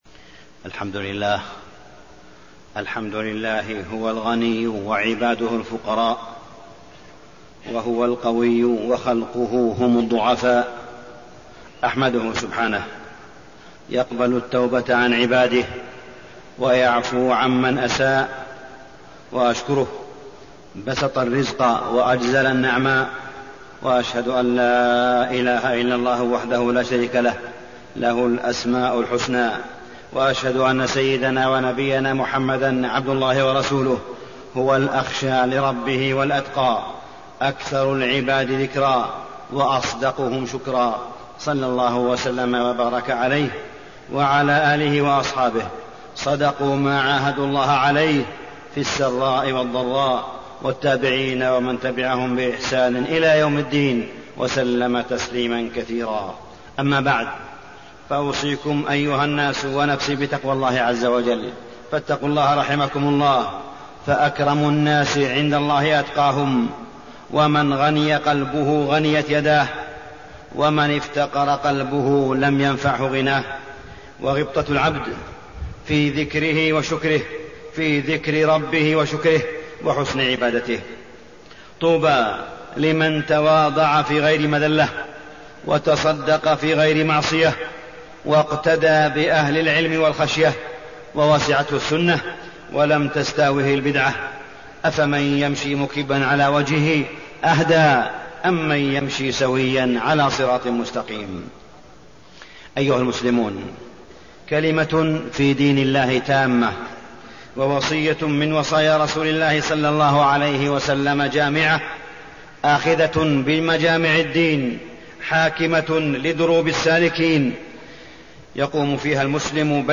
تاريخ النشر ٢ شعبان ١٤٣٠ هـ المكان: المسجد الحرام الشيخ: معالي الشيخ أ.د. صالح بن عبدالله بن حميد معالي الشيخ أ.د. صالح بن عبدالله بن حميد النكاح ومخالفات الأعراس